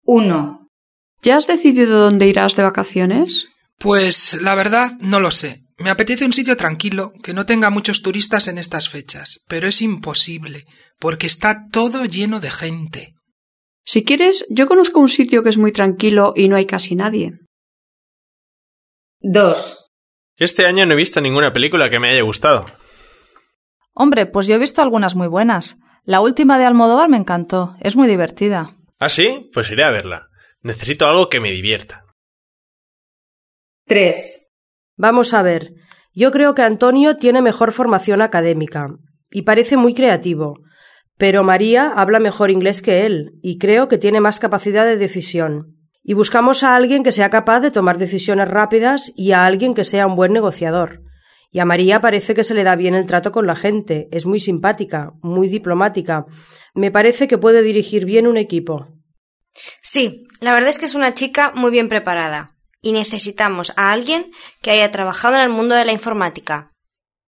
Las va a escuchar en tres diálogos.